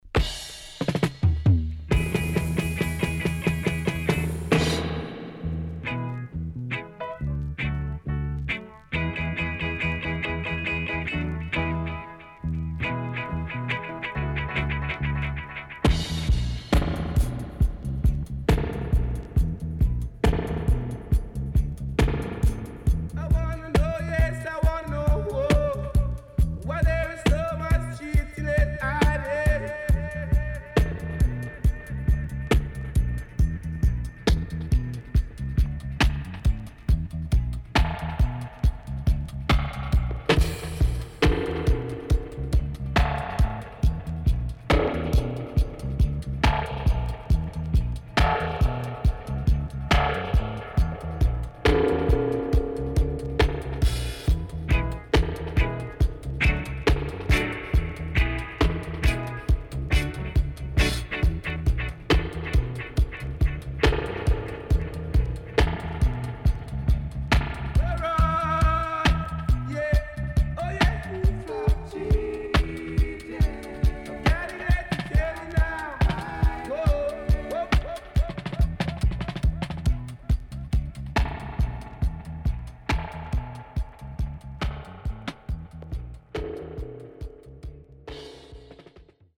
インパクトあるイントロが印象的なGreat Stepper Roots